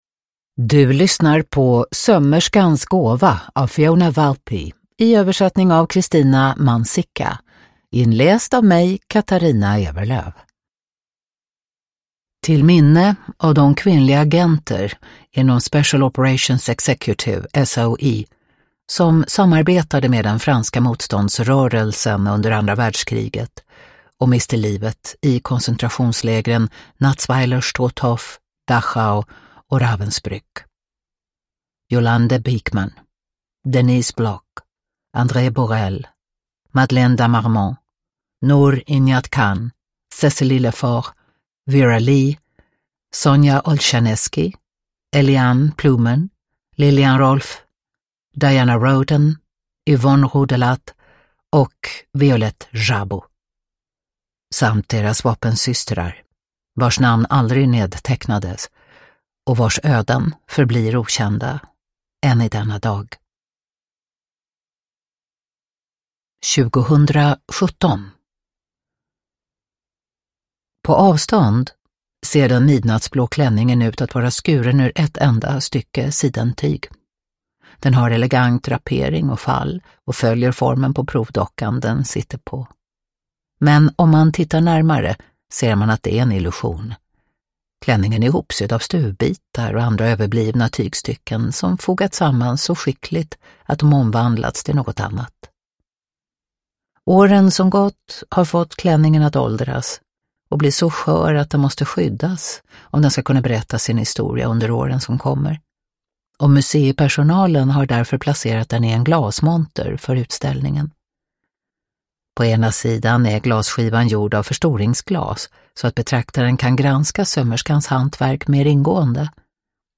Sömmerskans gåva – Ljudbok – Laddas ner
Uppläsare: Katarina Ewerlöf